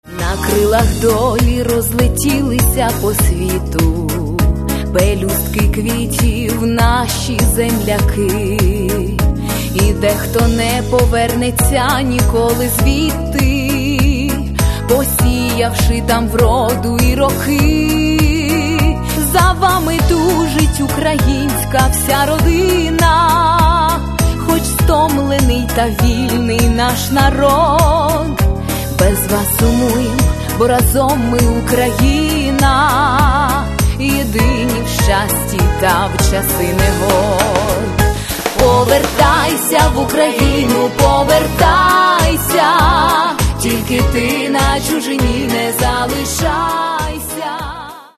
Поп (Легкая)
Каталог -> Поп (Легкая) -> Лирическая